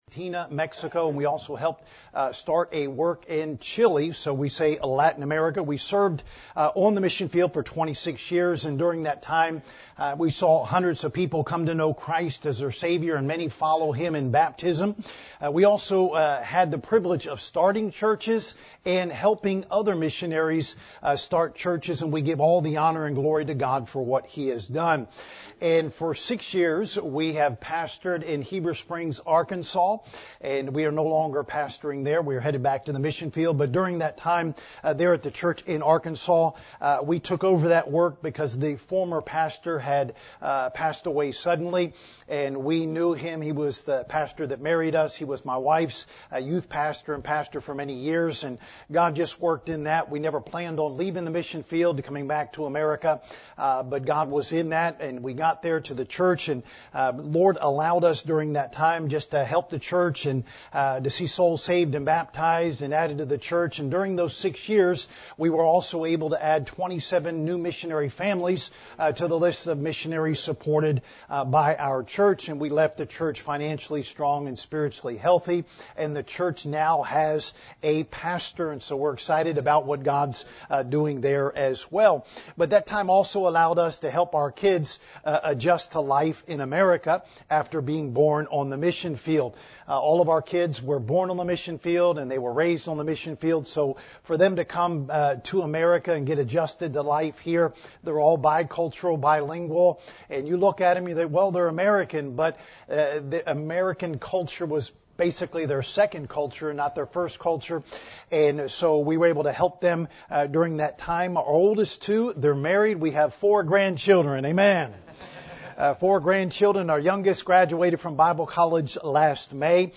This sermon walks through four basic, biblical needs of the lost and closes with clear steps we can take this week to be part of what God is doing.